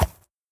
horse_soft2.ogg